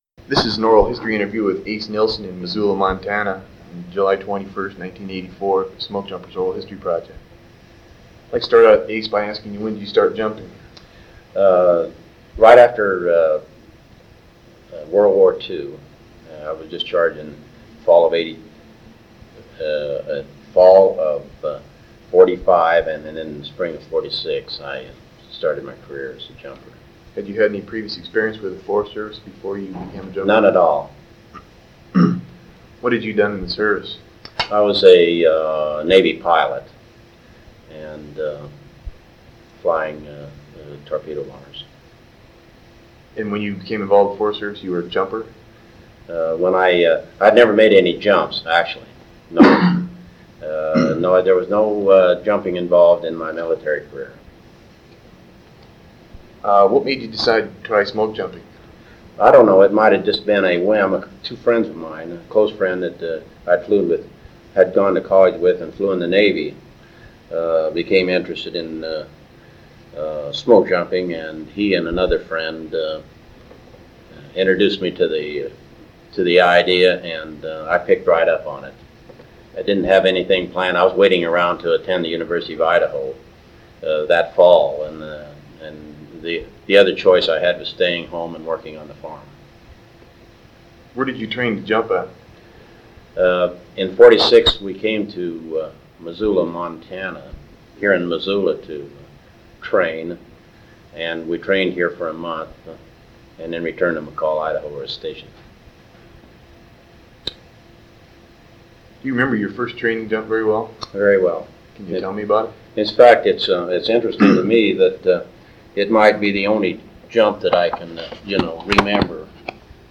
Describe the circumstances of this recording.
1 sound cassette (55 min.) : analog